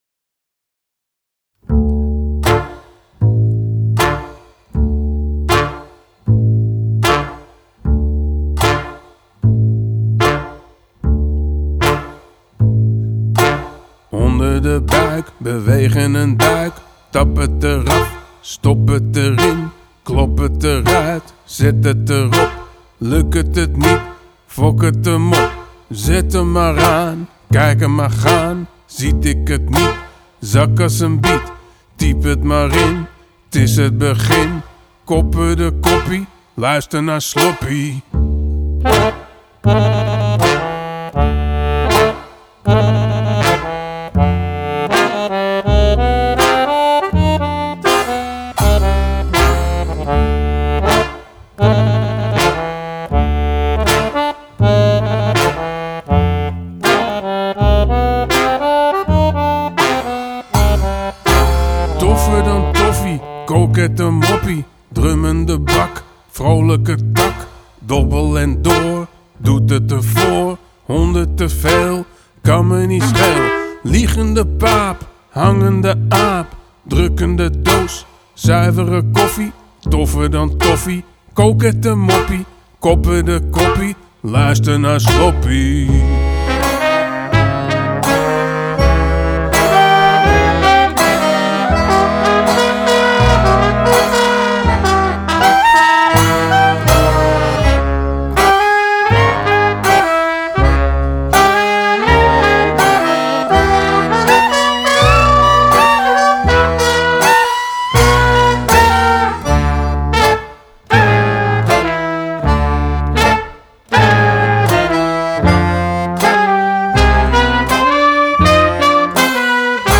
Genre: Klezmer, Folk, World